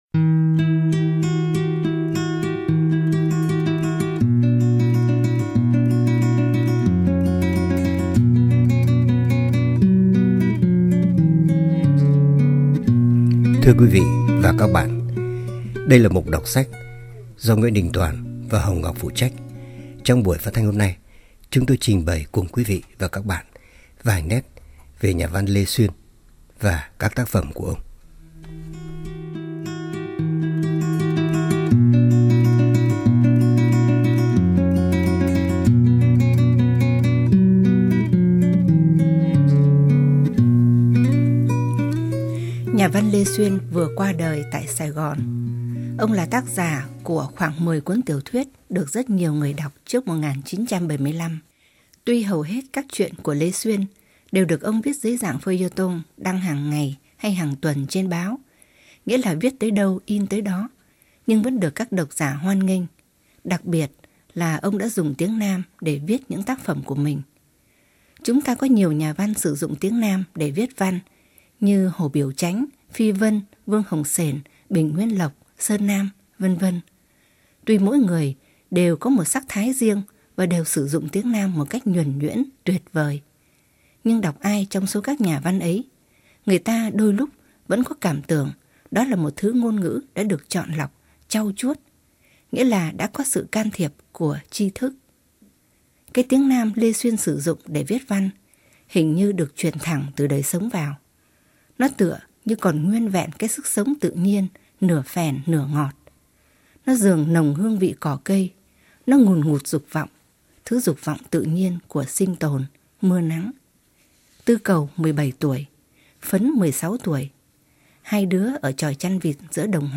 Ở cái giới hạn của thời lượng phát thanh, giọng của người đọc trở thành một yếu tố quan trọng trong việc chuyển tải nội dung.